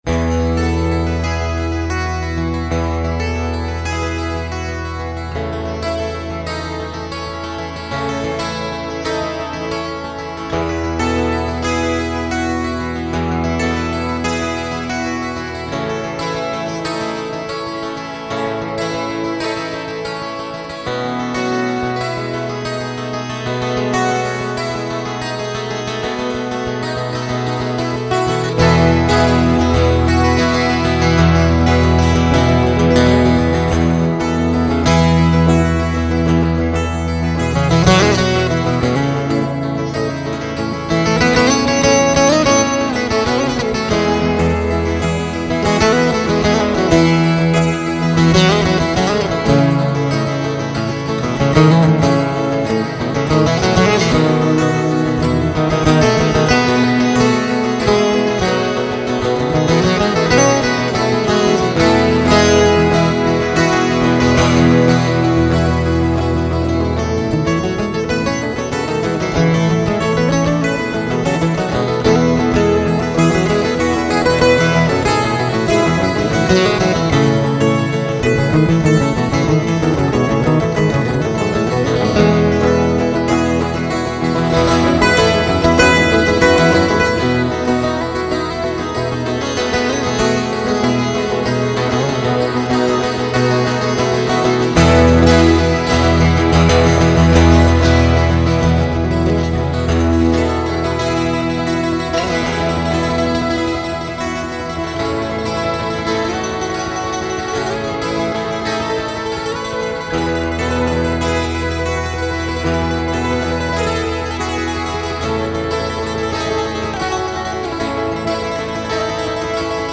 2919   02:50:00   Faixa: 7    Mpb